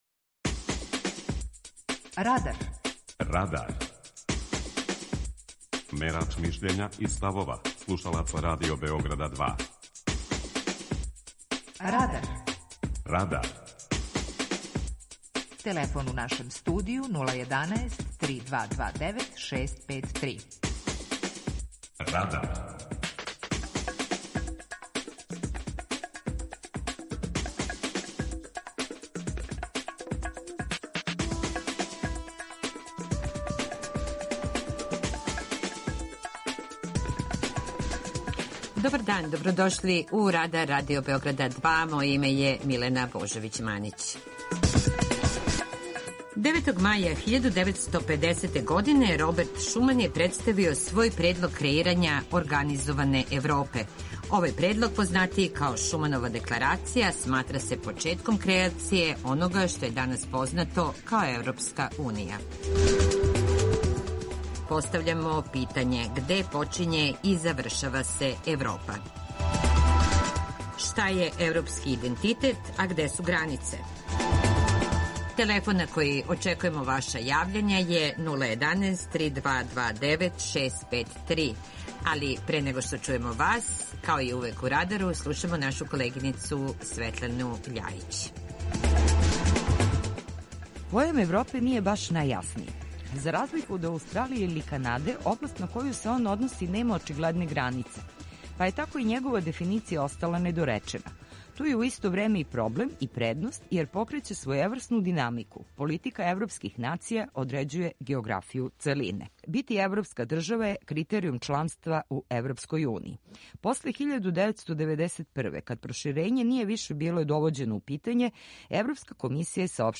Питање Радара: Где почиње и завршава се Европа? преузми : 19.11 MB Радар Autor: Група аутора У емисији „Радар", гости и слушаоци разговарају о актуелним темама из друштвеног и културног живота.